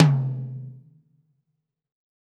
PTOM 5.wav